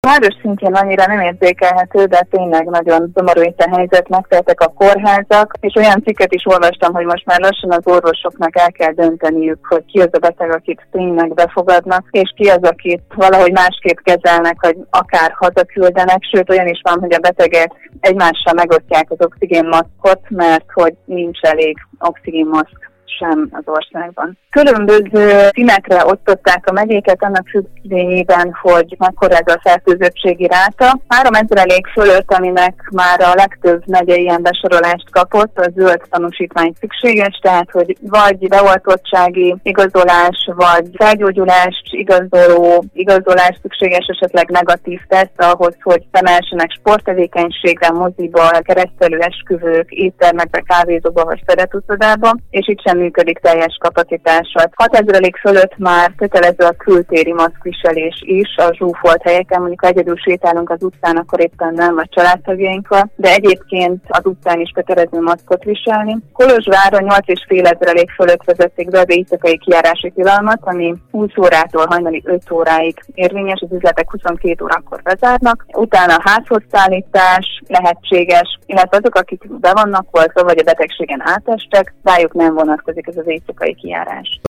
kolozsvar_riport.mp3